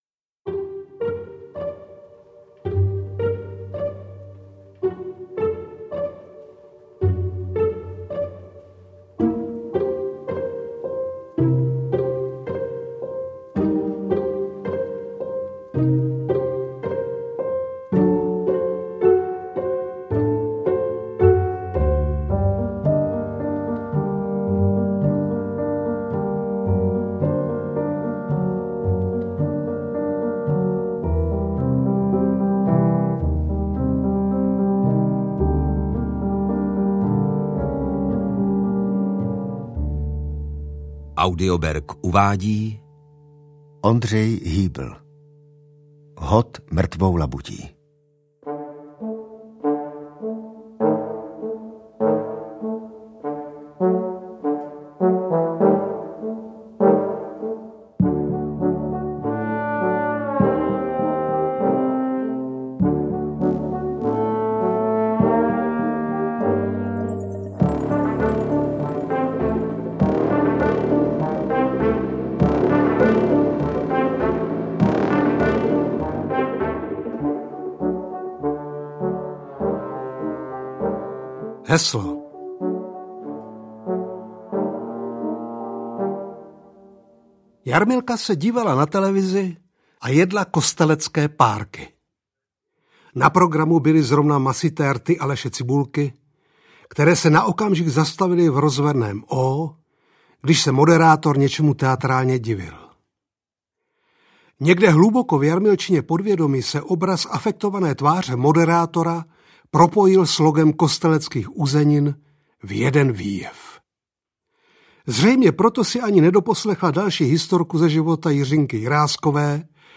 Hod mrtvou labutí audiokniha
Ukázka z knihy